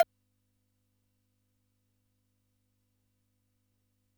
Index of /90_sSampleCDs/300 Drum Machines/Keytek MDP-40
009CWBELL.wav